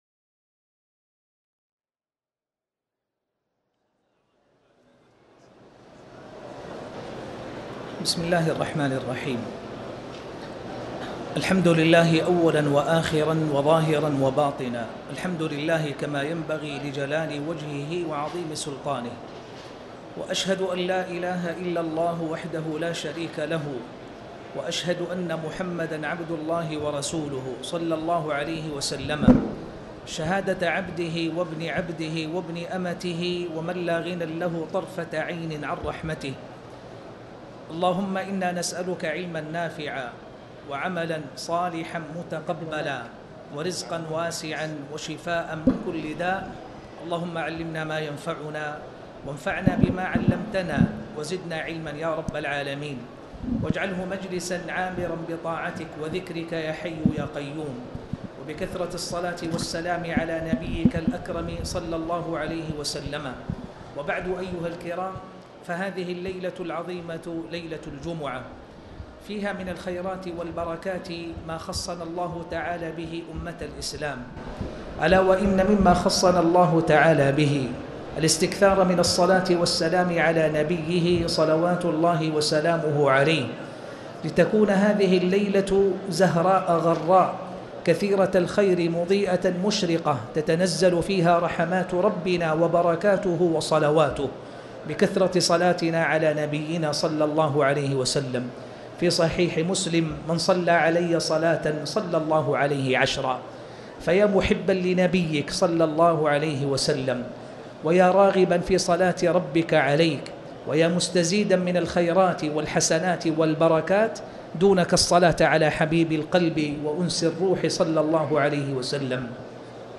تاريخ النشر ١٣ جمادى الآخرة ١٤٣٩ هـ المكان: المسجد الحرام الشيخ